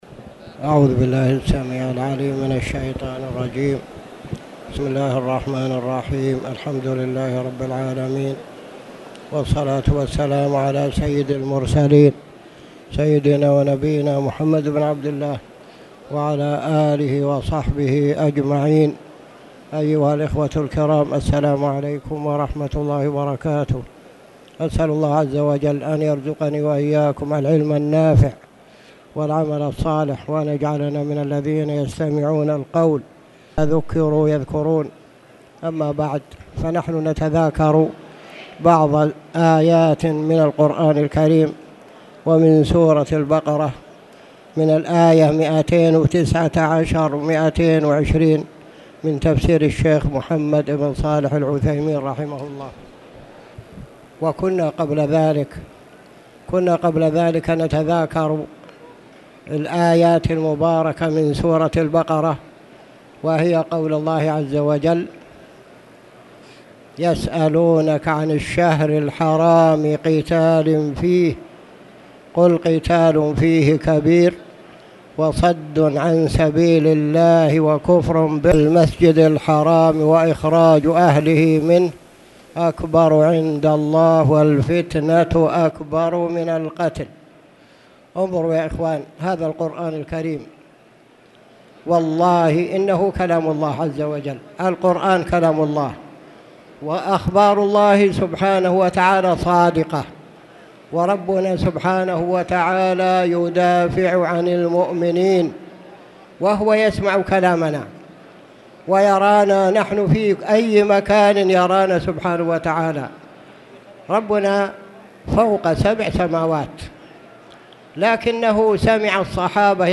تاريخ النشر ١٣ رمضان ١٤٣٧ هـ المكان: المسجد الحرام الشيخ